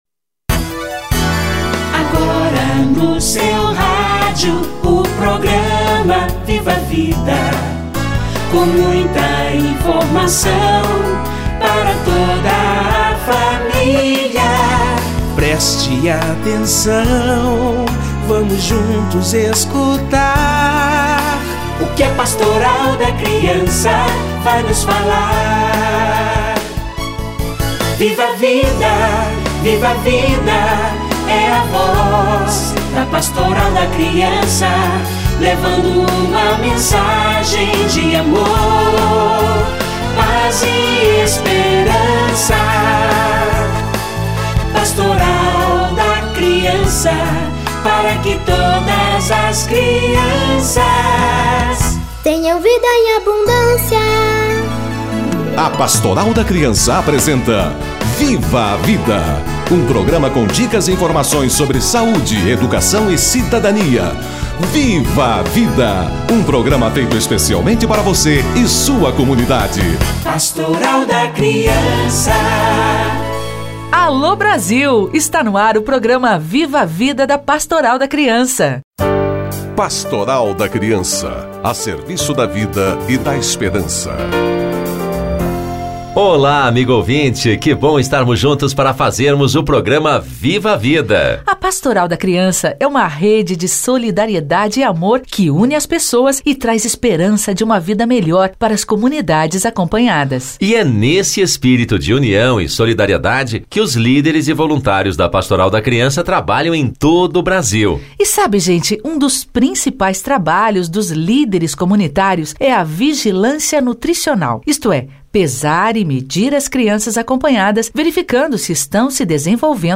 Vigilância Nutricional - Entrevista